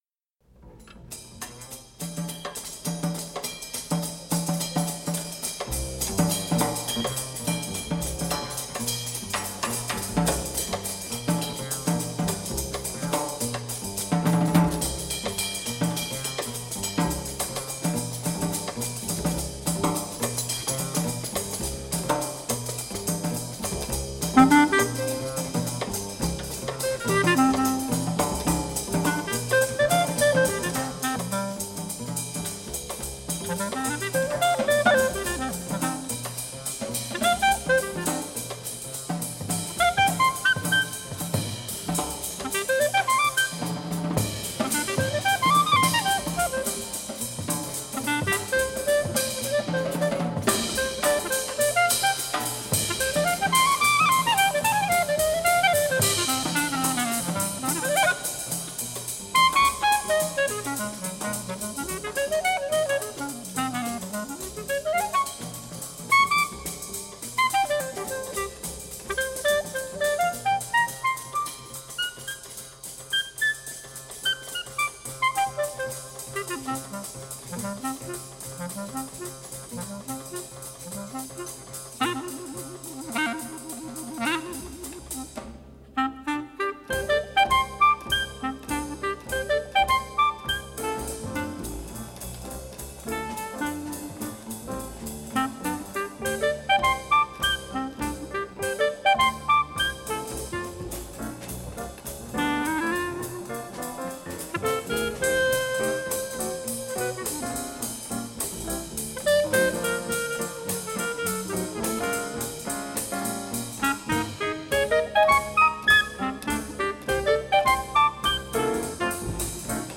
live at Prague International Jazz Festival
One of the more underrated and overlooked Jazz clarinetists
Cool School Jazz